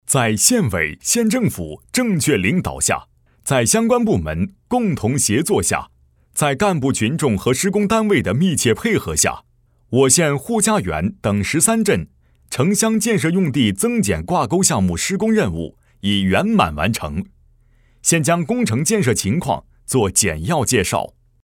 男27号